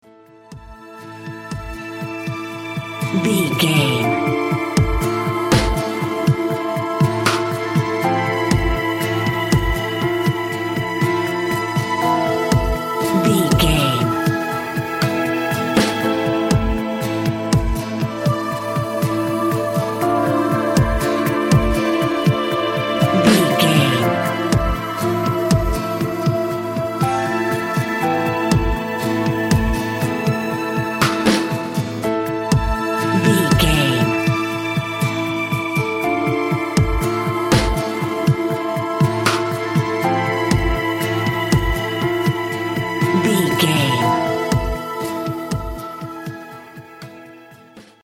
Aeolian/Minor
eerie
drums
banjo
guitar
double bass
folk instruments